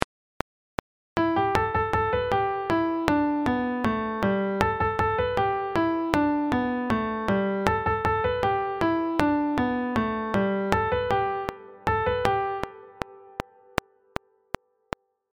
４週目だけ、メロディを改変しましょう！
１週目の出だしの前に、ちょこっとメロディを付け足して